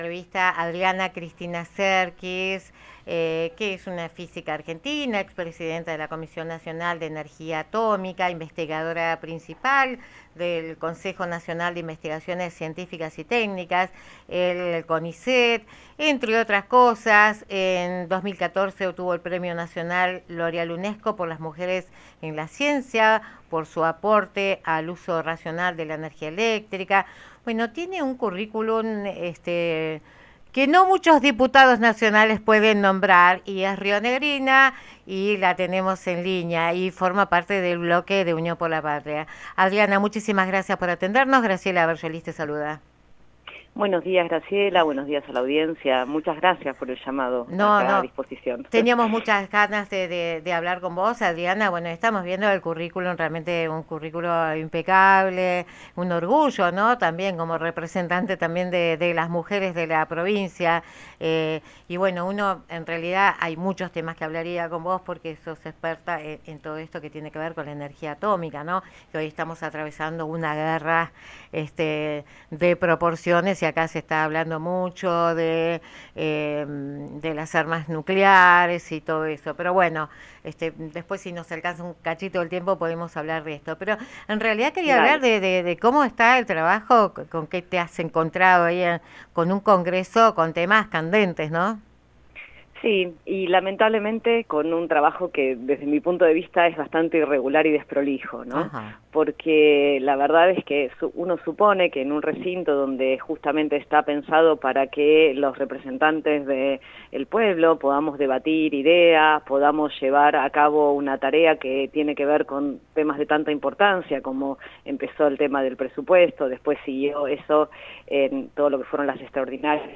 Episodio 17 – Entrevistas 2026 – Comunidad de la FM Mural